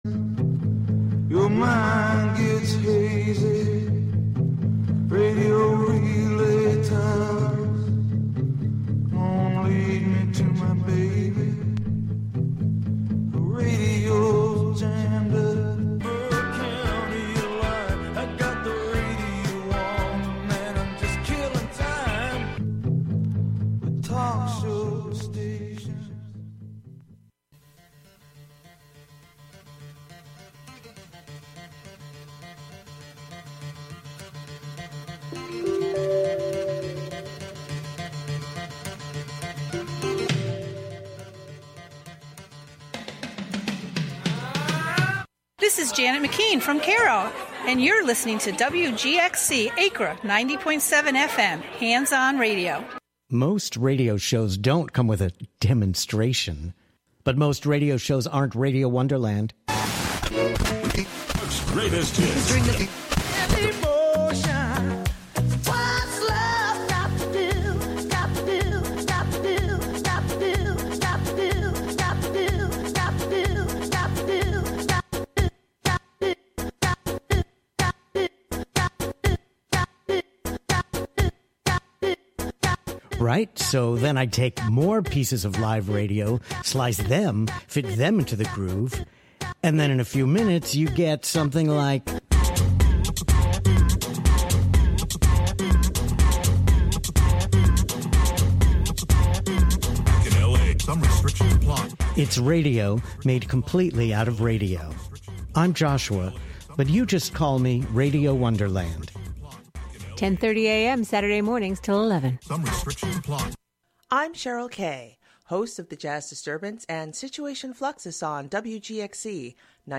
Counting down ten new sounds, stories, or songs, "American Top 40"-style.